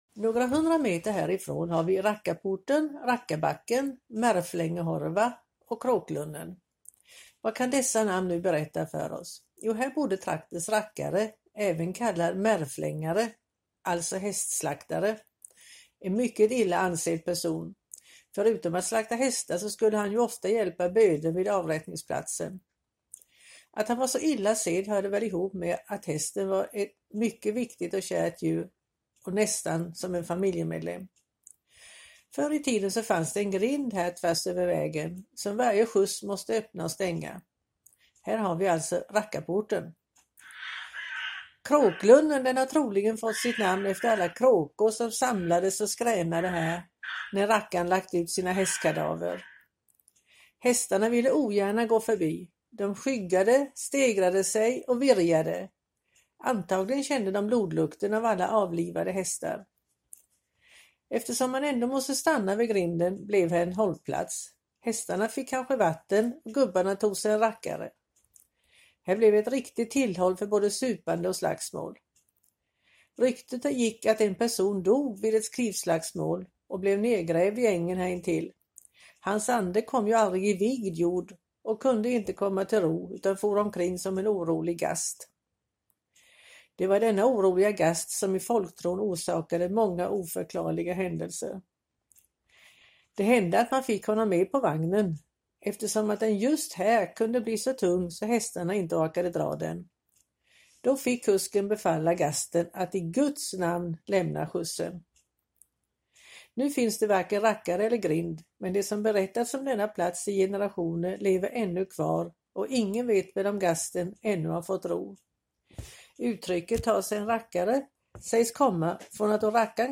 Berättarbänk